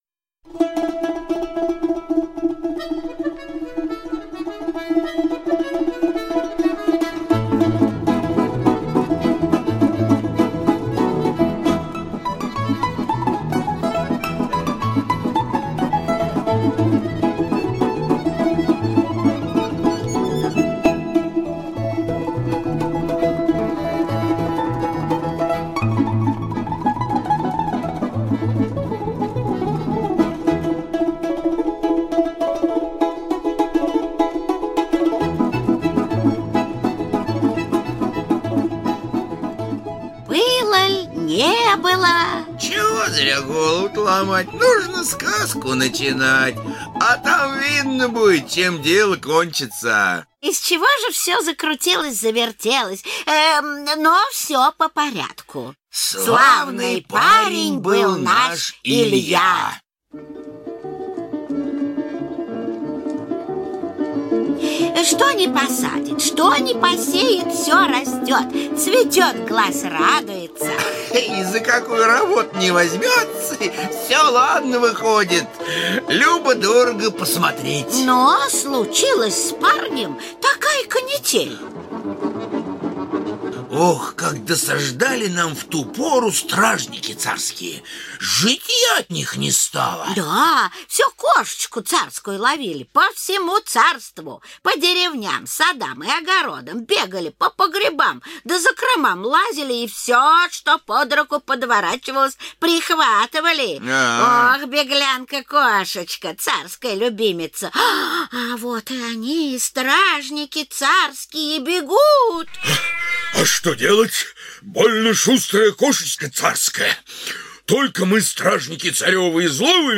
Аудиокнига Злыдень | Библиотека аудиокниг